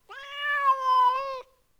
meow3.wav